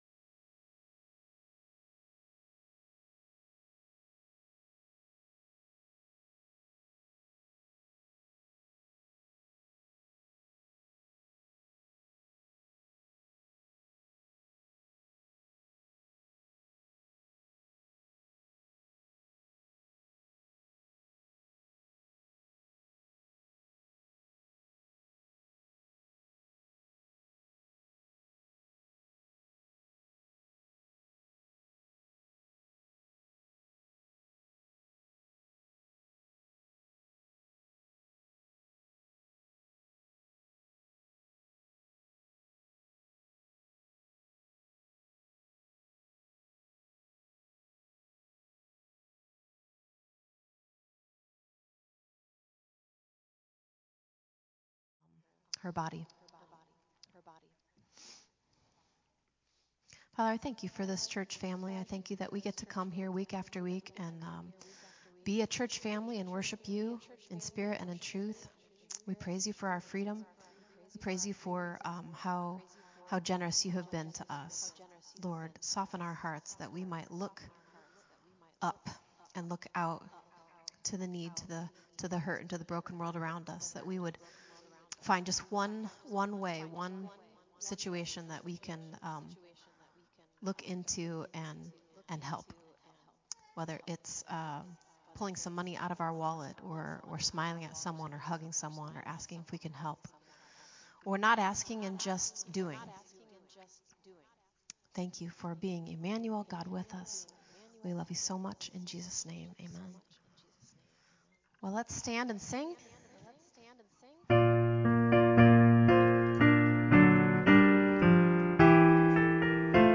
Our apologies to everyone, the announcements and the prayer requests are missing. we had some technical difficulties at the beginning of service but were able to get it back.